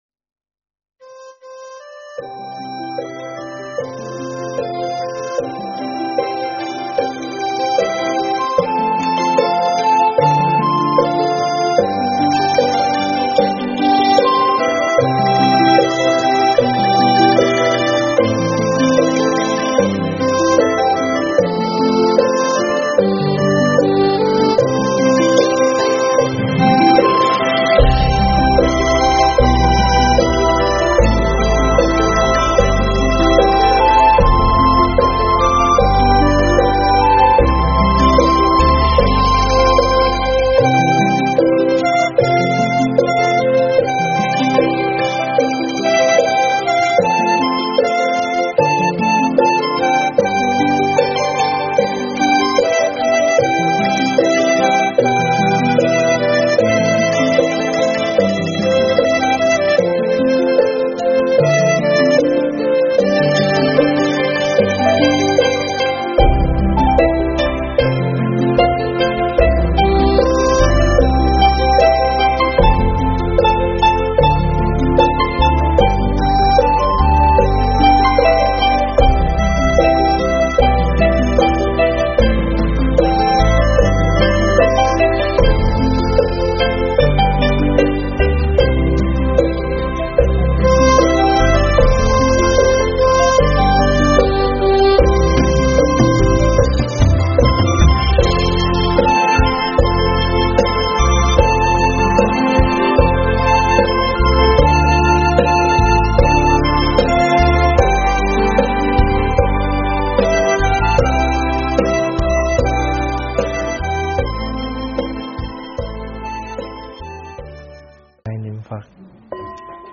Thuyết pháp Các Căn Viên Thông